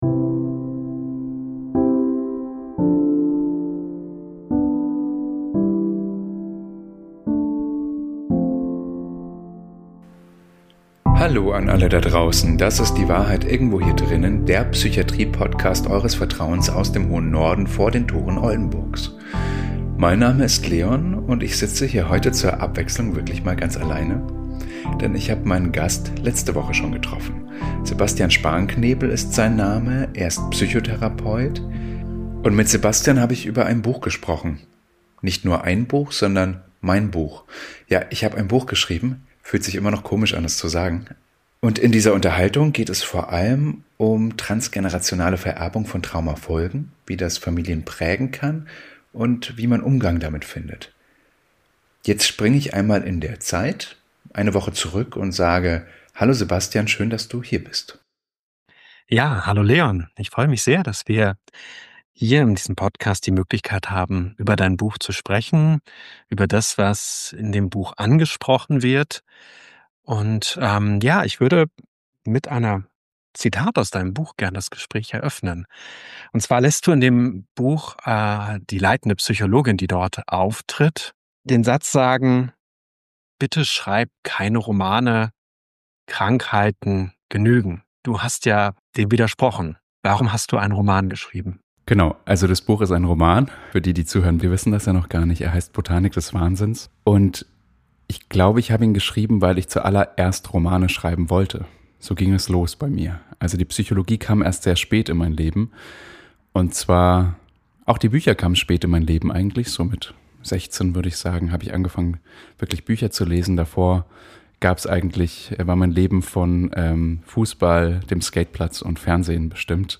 #39 TRANSGENERATIONALES TRAUMA Gespräch über den Roman "Botanik des Wahnsinns" ~ Die Wahrheit Irgendwo Hier Drinnen Podcast